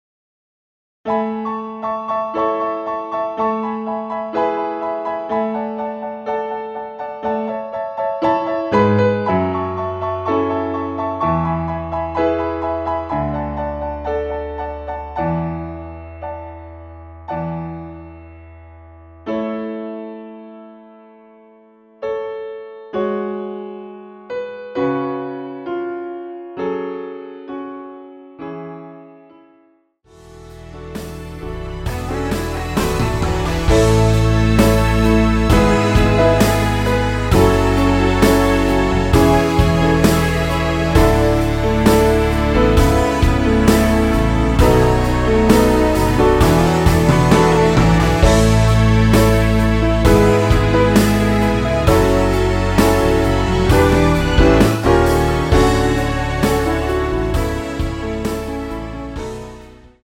남자키에서 (-2) 더 내린 MR 입니다.(미리듣기 참조)
앞부분30초, 뒷부분30초씩 편집해서 올려 드리고 있습니다.
중간에 음이 끈어지고 다시 나오는 이유는